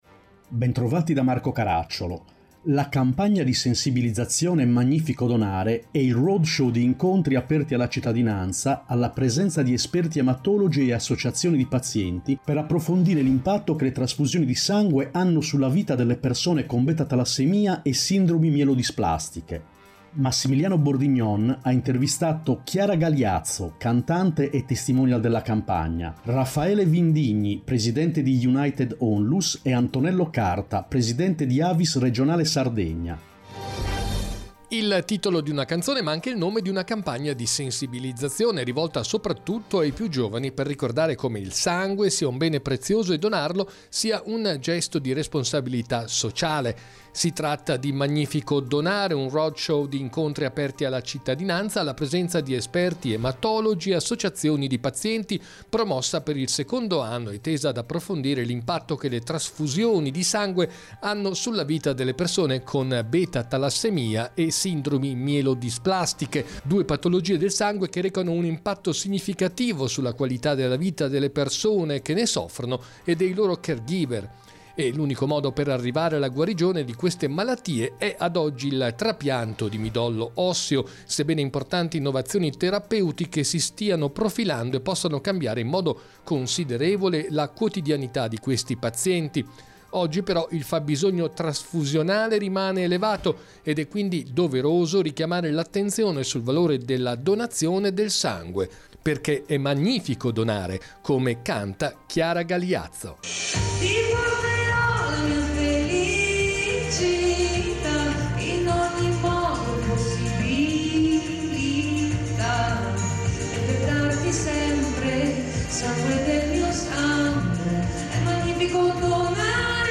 Chiara Galiazzo, Cantante e testimonial della campagna “Magnifico Donare”
Puntata con sigla